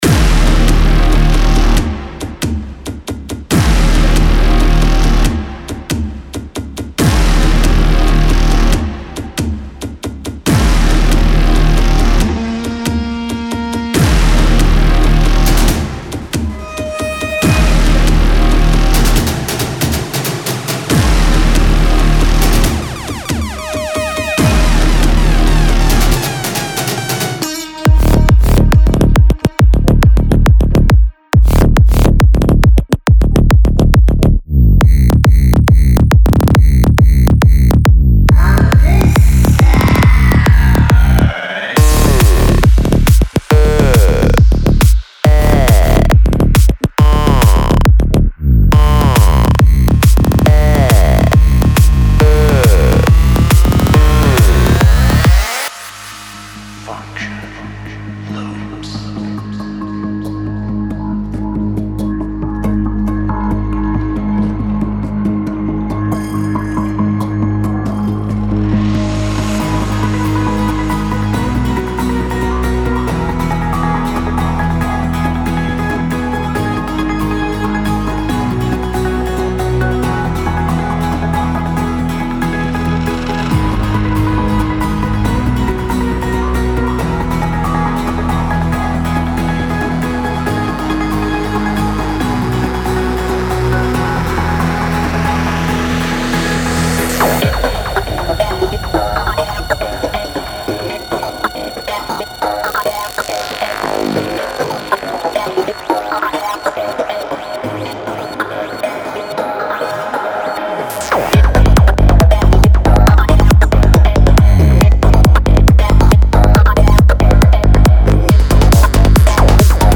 期待已久的Psytrance和Progressive Psy制作者工具包已经到货。
●003 x Mixed & Mastered FullMix/Preview Demos (.WAVs)
●030 x Drum Loops
●012 x Vocal Loops
●057 x Music Loops – (Synths/Leads/Ethnic)